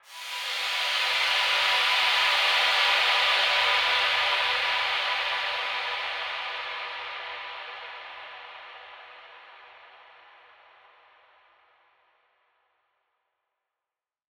SaS_HiFilterPad08-C.wav